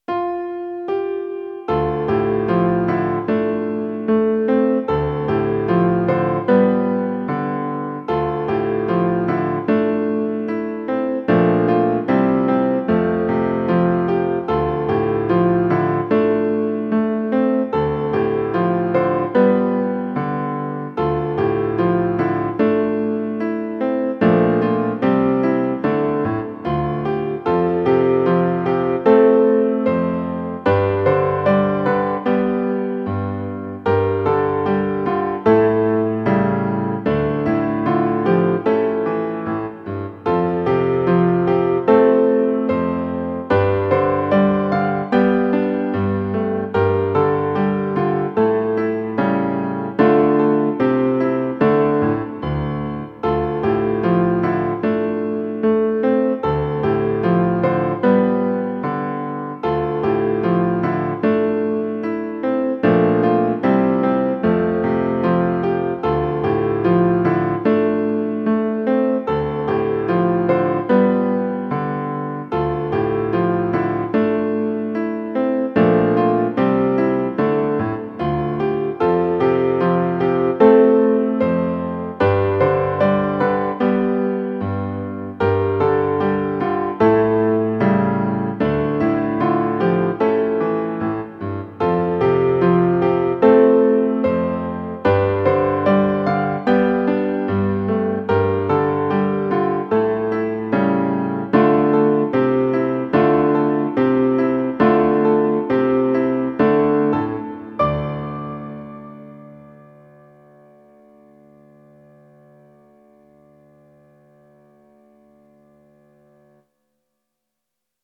ゆったりとしたテンポのジブリ風ピアノソロを無料音楽素材として公開しています。
イメージ：古代、遺跡、記憶　ジャンル：ジブリっぽいピアノソロ
今はもう無い、寂しい感じが伝わると嬉しいです。